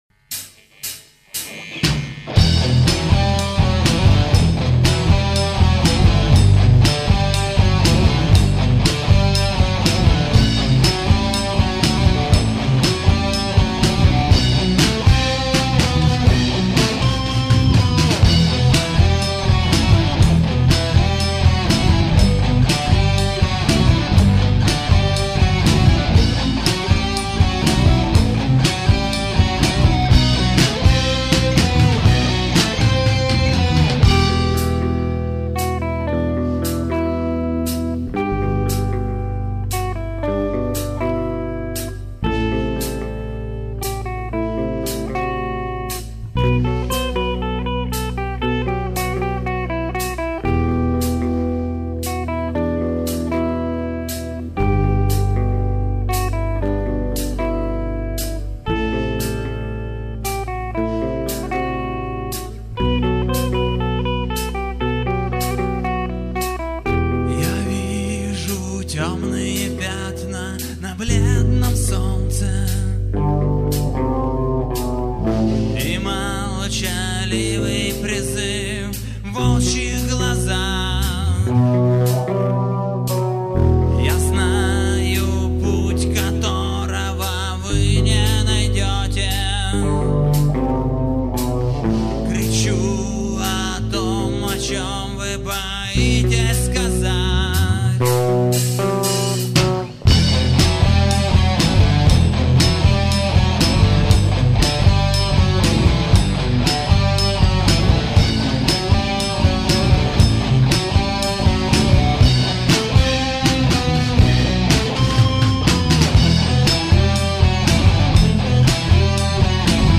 вокал, гитара
бас-гитара